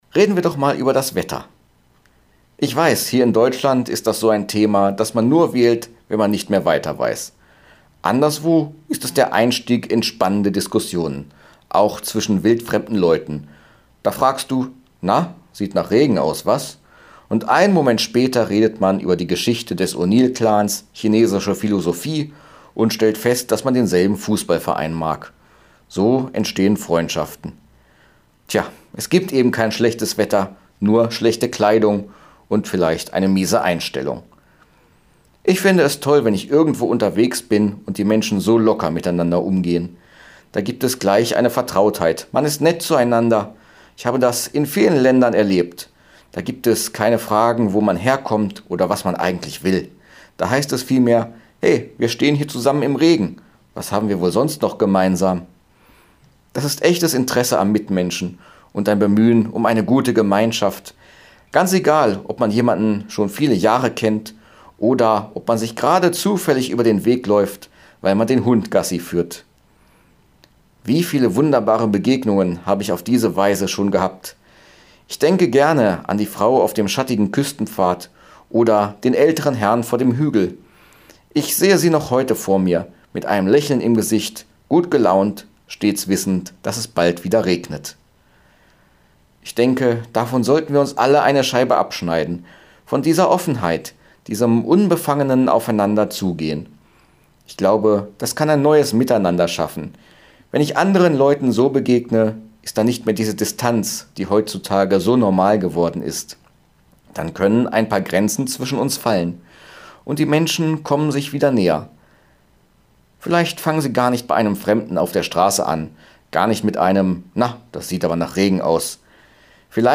Radioandacht vom 9. August